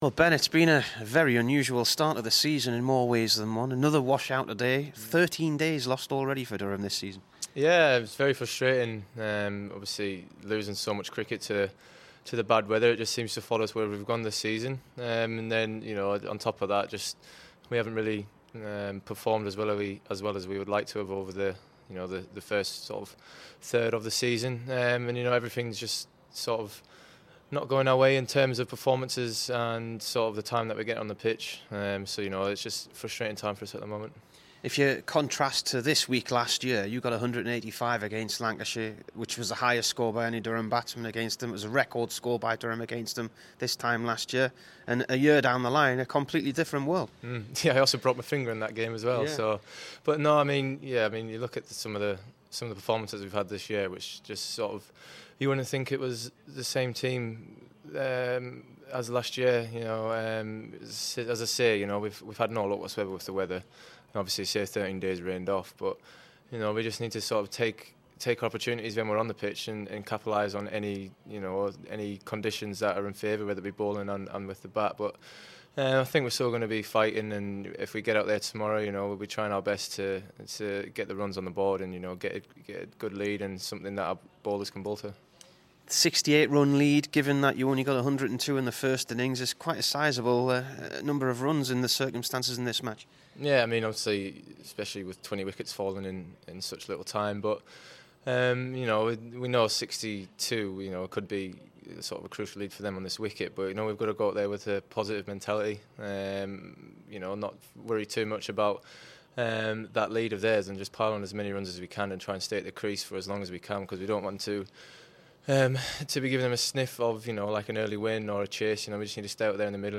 BEN STOKES INTERVIEW